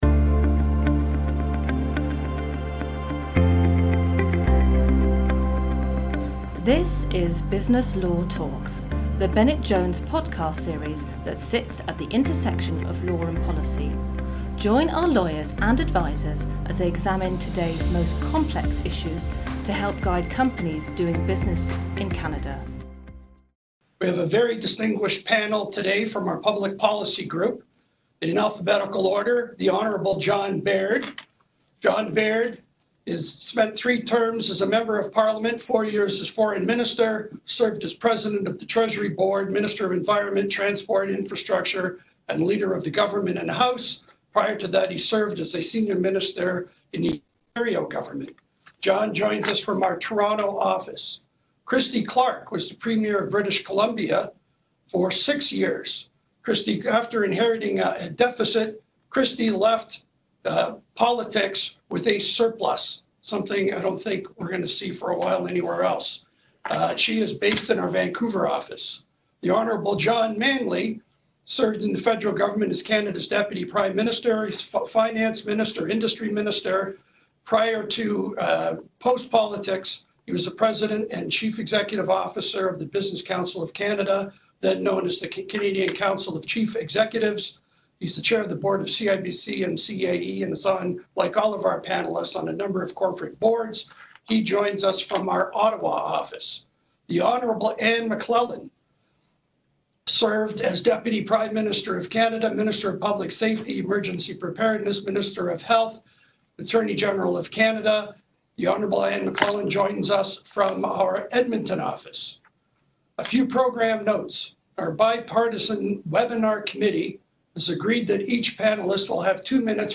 In this recording from a web event following the U.S. election
They discuss what the U.S. election results could mean for Canadian foreign policy, trade, our economic recovery and key sectors such as energy. The panelists in this event are the Honourable John R. Baird P.C., Christy Clark, the Honourable John P. Manley P.C., O.C., and the Honourable A. Anne McLellan P.C., O.C., A.O.E.